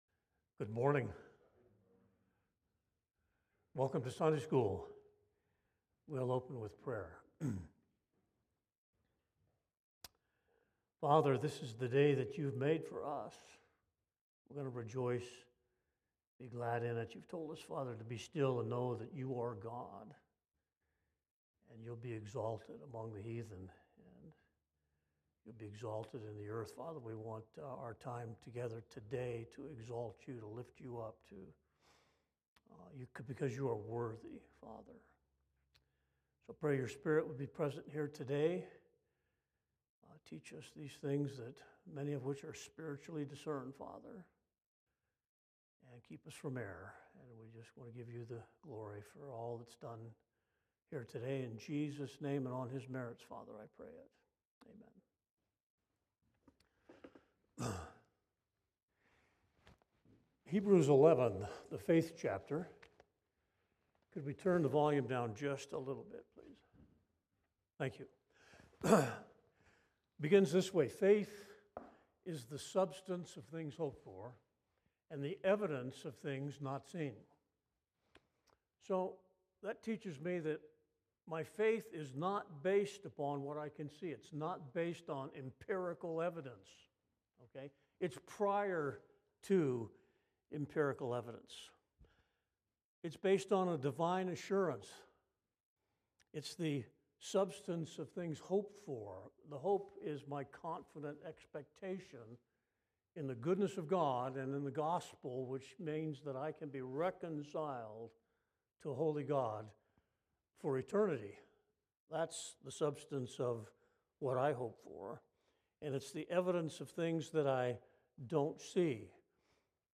Lesson 10 (Sunday School)